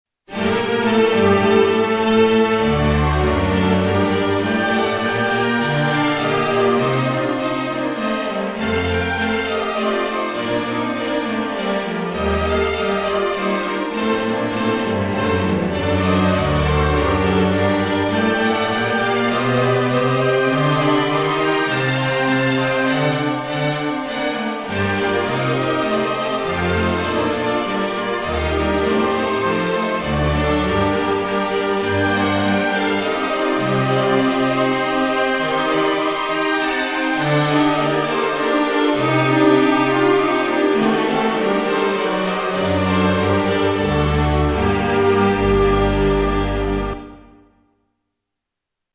Kleines Allegro für Streicher